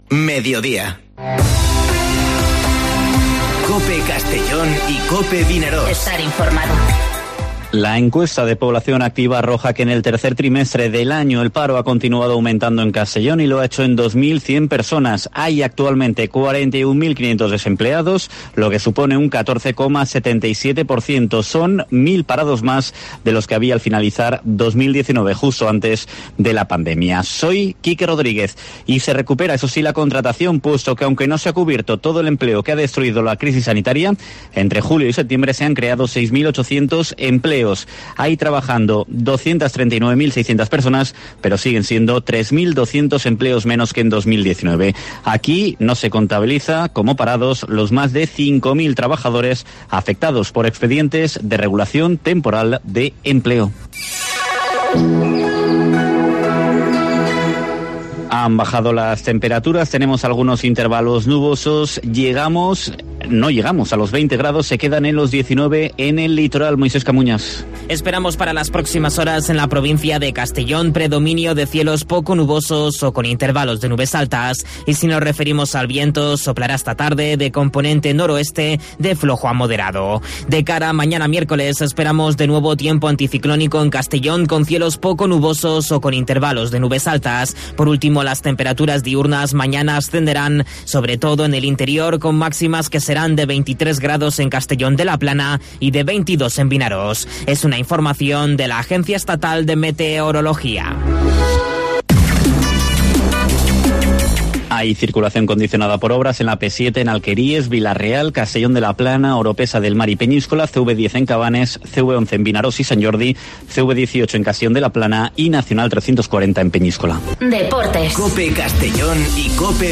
Informativo Mediodía COPE en la provincia de Castellón (27/10/2020)